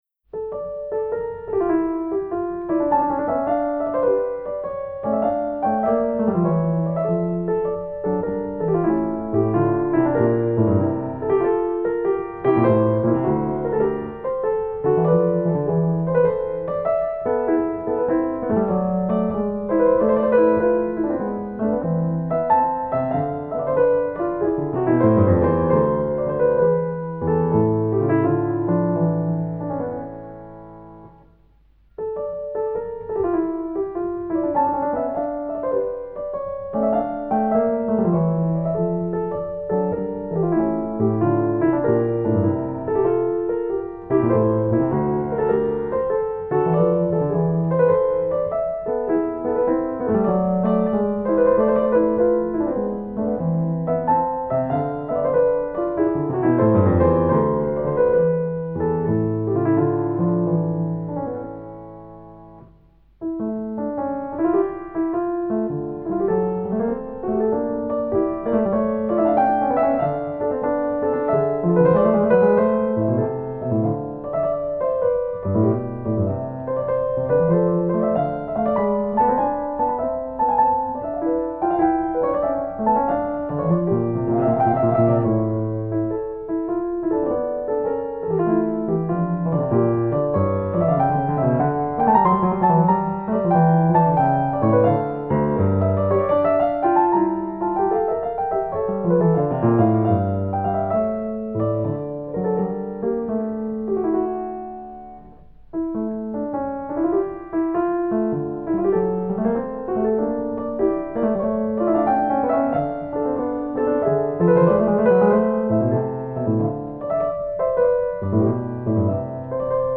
J.S.Bach_French_Suite_BWV_812_D_Minor_6_Gigue
J.S.Bach_French_Suite_BWV_812_D_Minor_6_Gigue.mp3